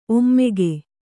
♪ ommege